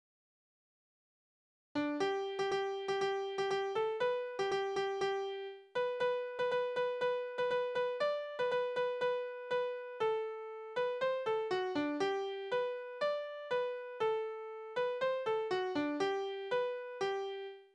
« 10905 » Kegelkönig Tanzverse: Kegel Tonart: G-Dur Taktart: C (4/4) Tonumfang: Oktave Besetzung: instrumental Anmerkung: Aus einer Bemerkung am Rand geht hervor, dass es sich um ein Tanzlied handelt.